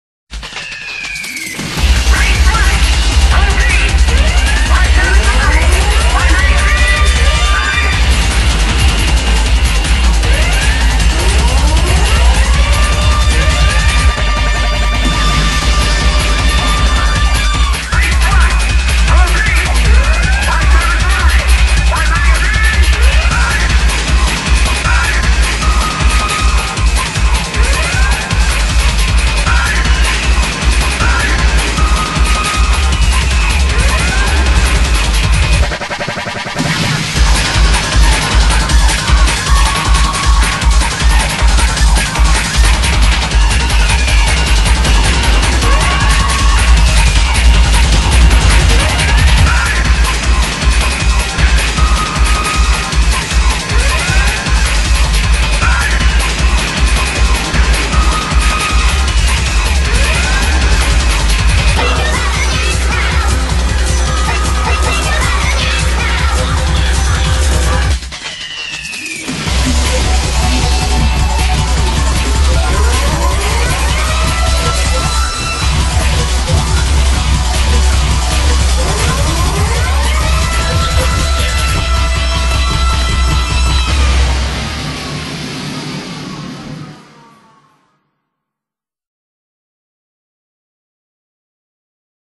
BPM312
Audio QualityPerfect (Low Quality)